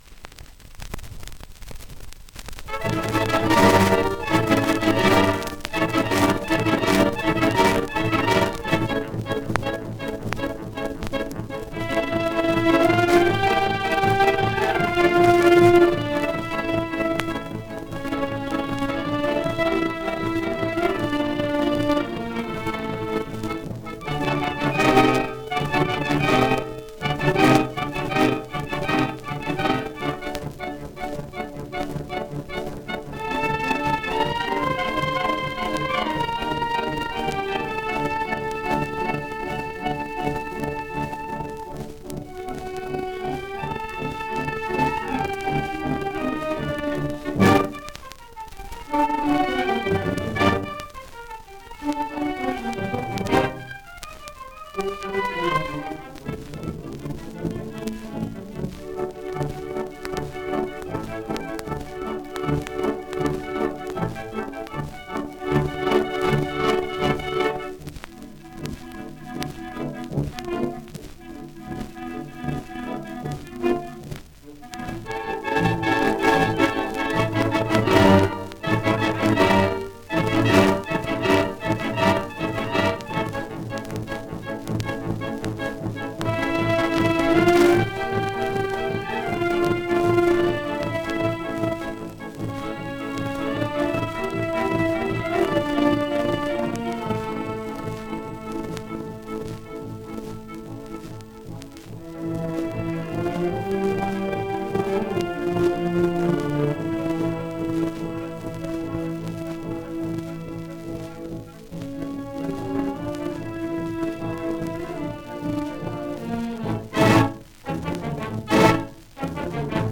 1 disco : 78 rpm ; 30 cm Banda del Real Cuerpo de Alabarderos, Madrid